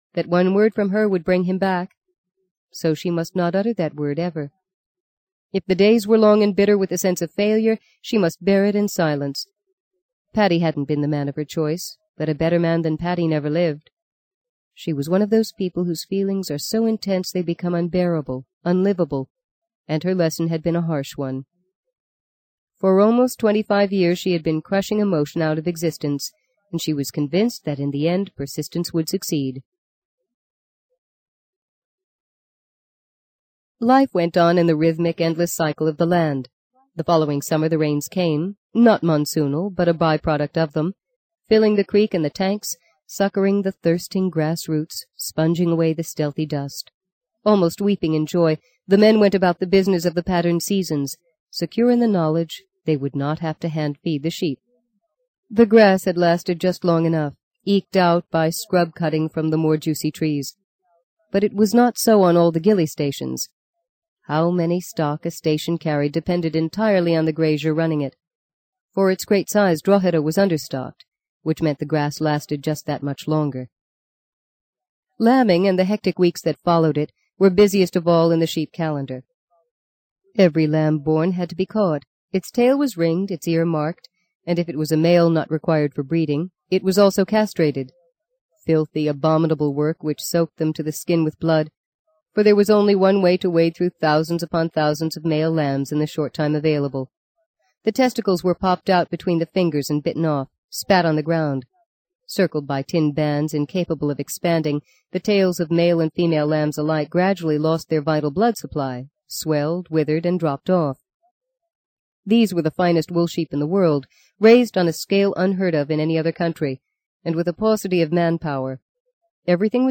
在线英语听力室【荆棘鸟】第六章 15的听力文件下载,荆棘鸟—双语有声读物—听力教程—英语听力—在线英语听力室